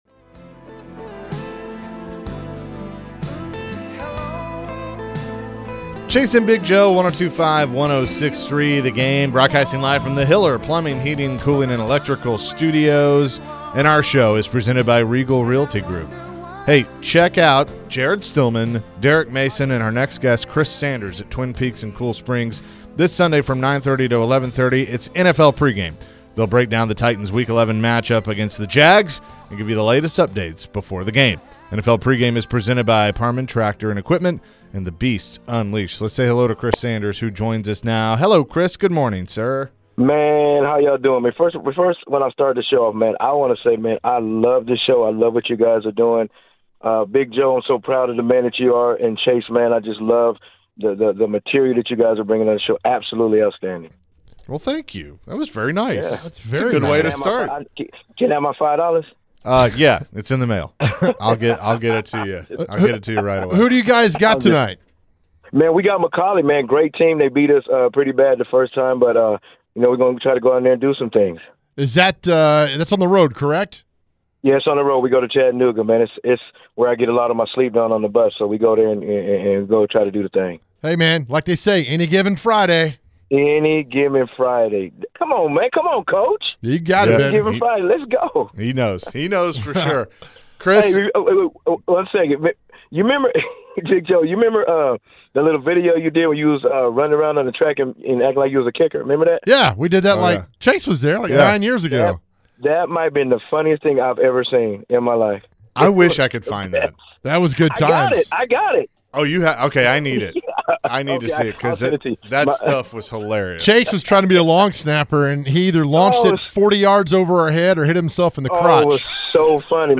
Forever Titans wide receiver Chris Sanders joined the show and shared his thoughts about the Titans offense and their upcoming game against the Jacksonville Jaguars.